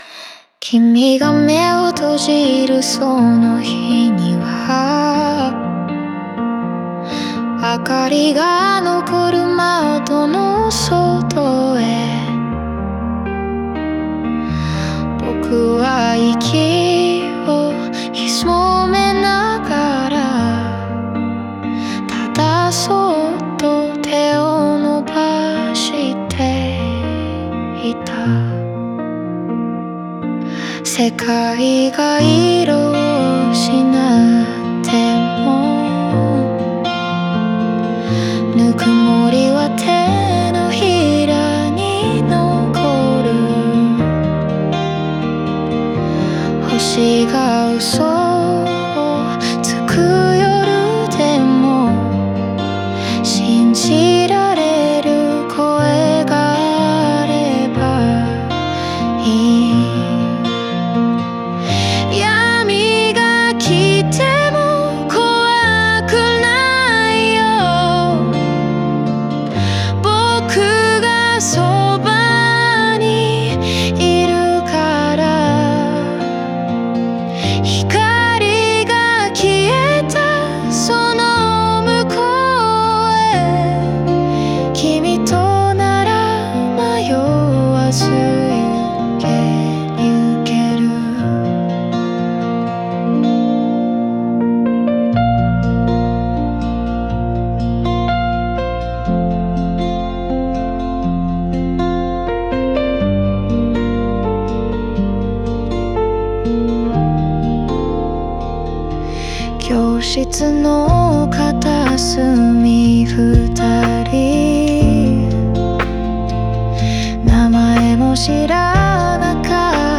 オリジナル曲♪
人の生と死に寄り添うような、静かで崇高な愛の歌です。